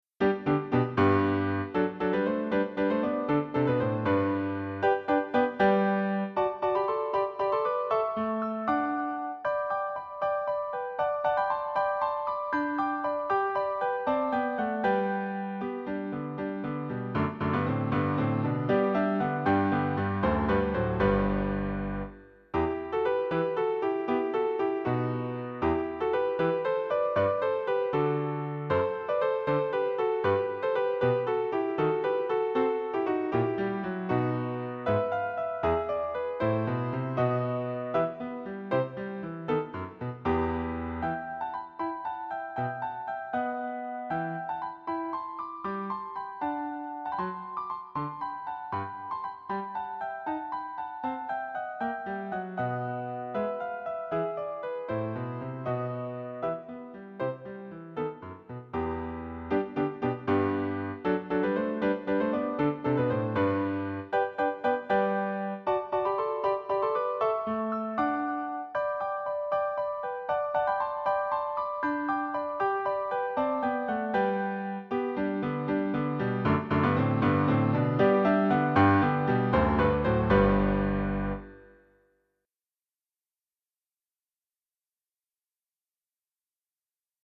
Most of these pieces I performed myself in public concerts.
The sound quality is slightly better on the CD.